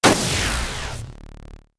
Звуки получения урона
Звук поражения в игре